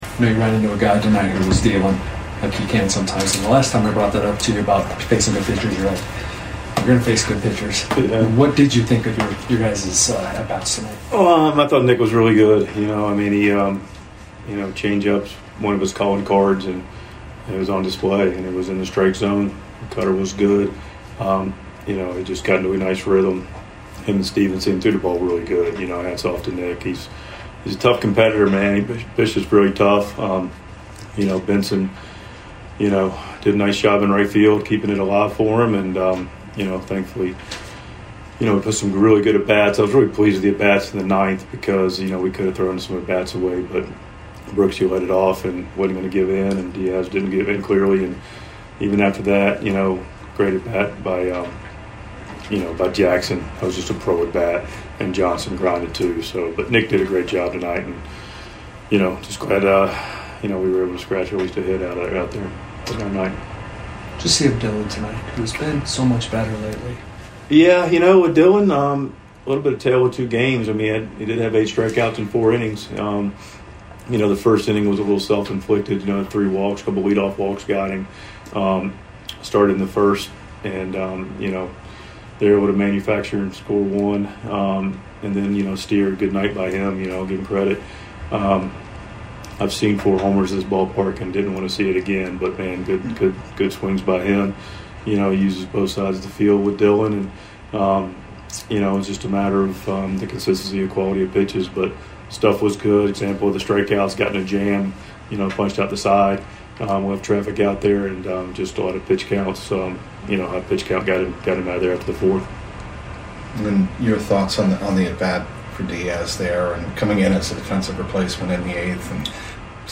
Mike Shildt's postgame reaction after Friday's 8-1 loss to the Reds.